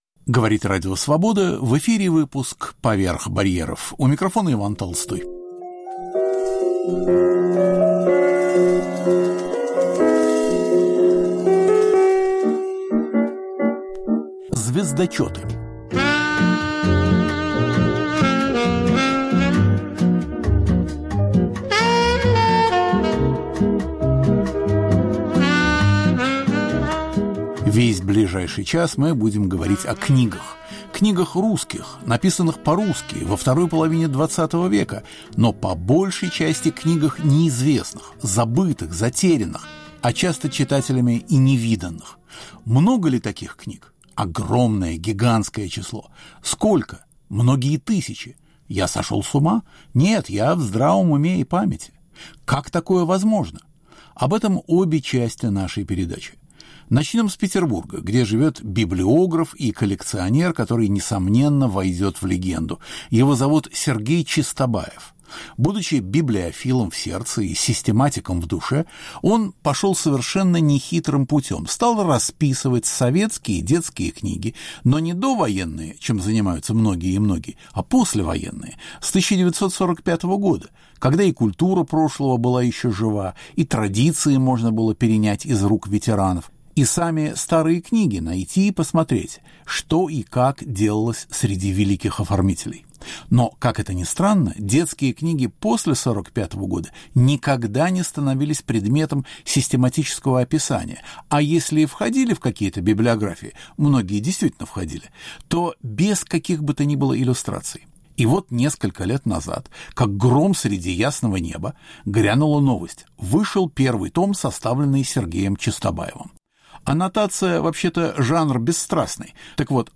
Две беседы с книжниками.